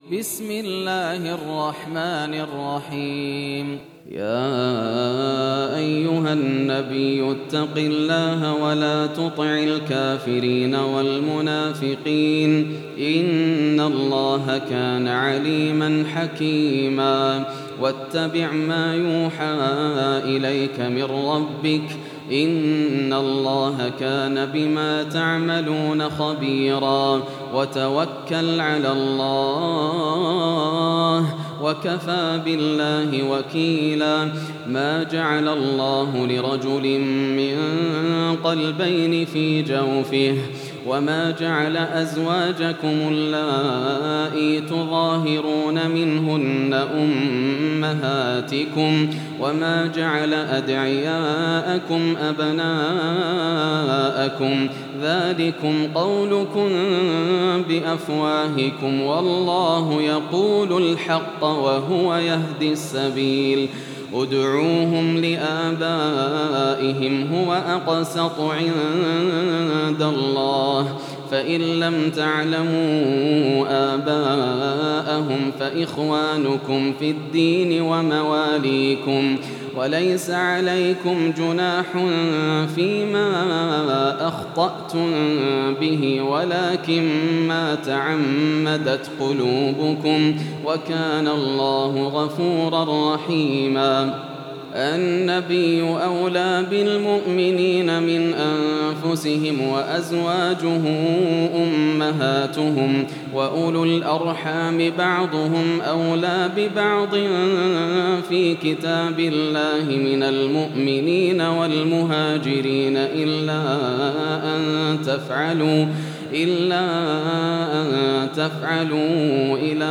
سورة الأحزاب > السور المكتملة > رمضان 1433 هـ > التراويح - تلاوات ياسر الدوسري